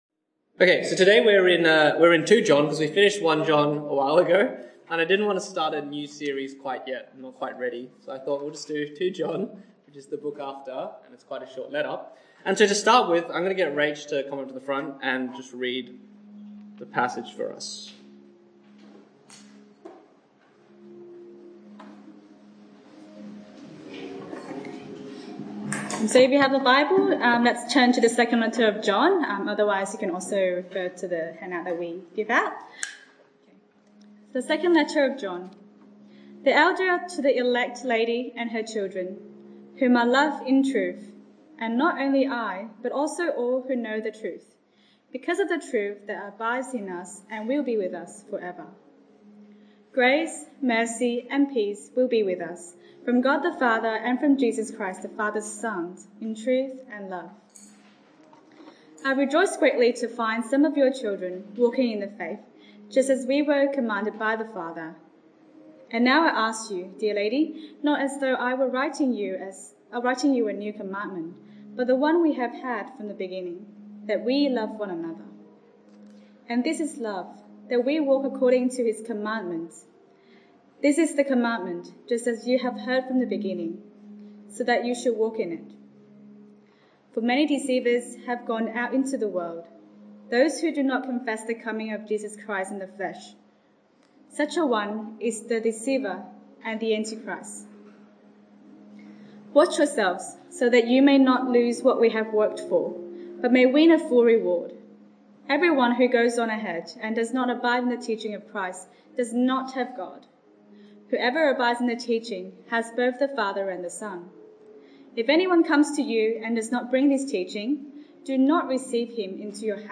This is the first part of two talks on the letter of 2 John.